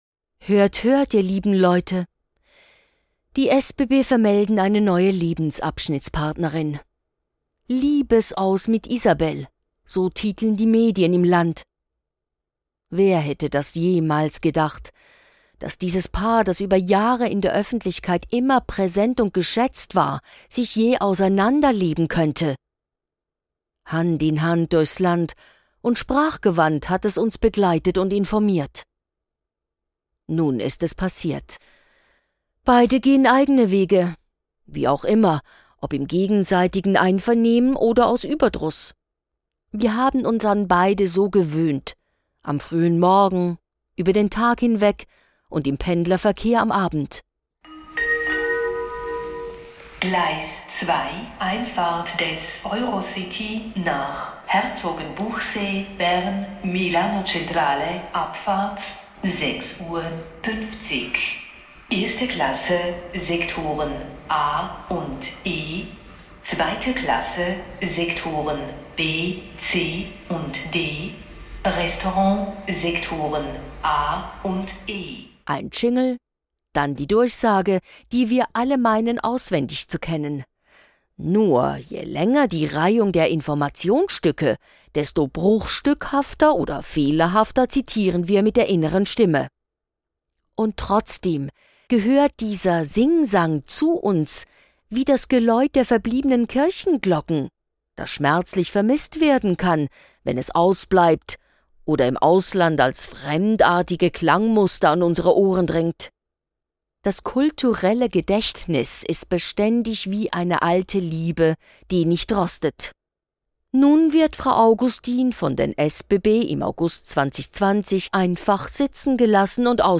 gelesen von